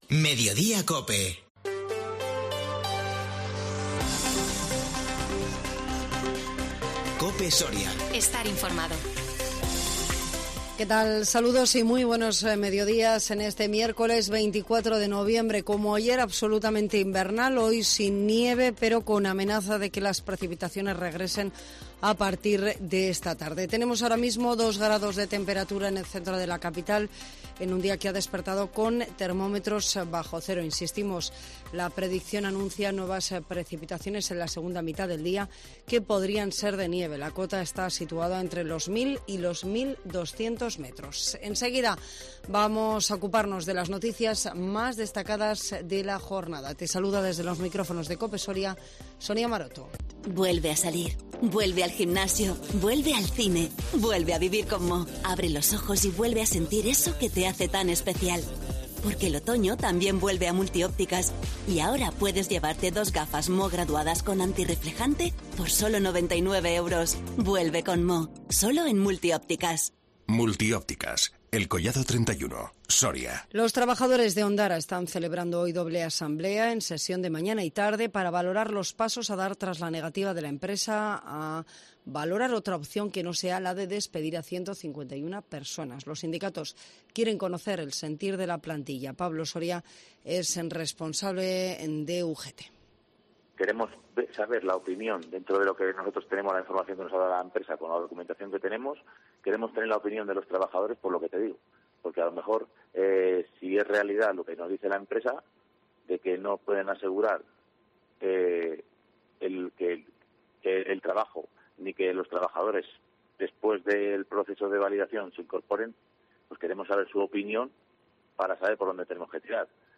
INFORMATIVO MEDIODÍA 24 NOVIEMBRE 2021